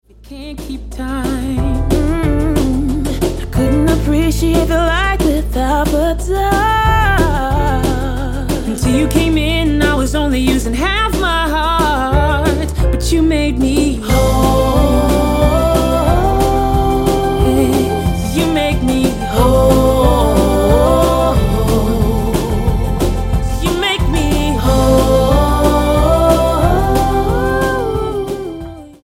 eclectic neo-soul gospel hitmaker
Style: Gospel